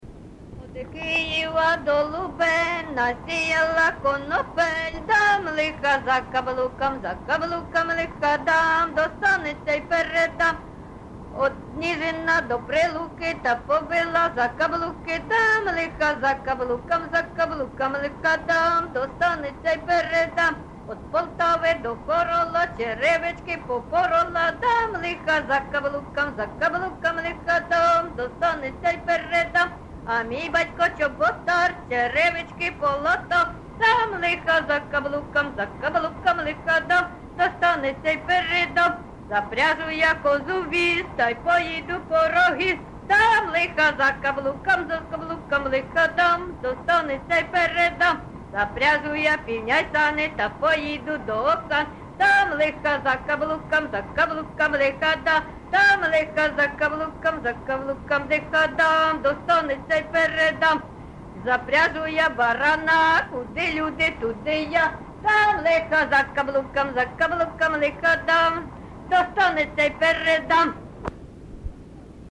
ЖанрЖартівливі
Місце записус. Харківці, Миргородський (Лохвицький) район, Полтавська обл., Україна, Полтавщина